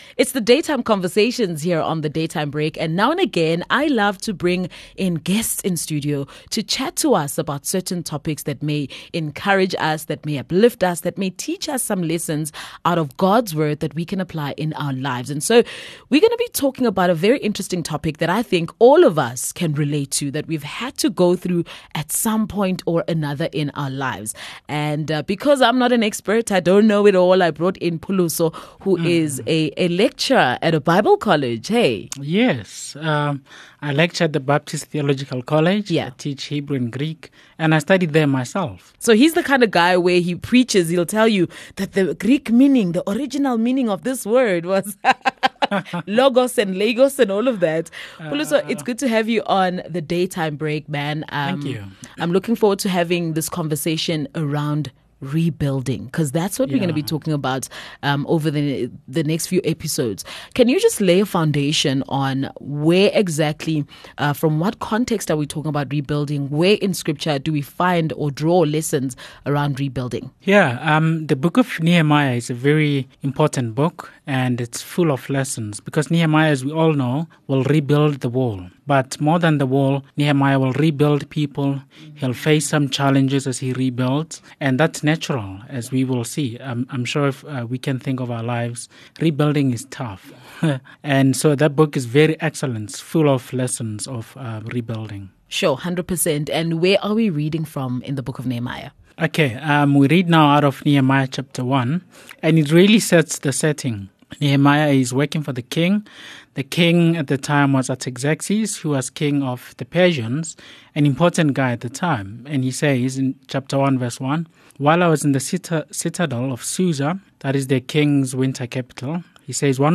On this channel, we share some of our presenters most valuable and encouraging links from their shows, interviews with guests and other other valuable content.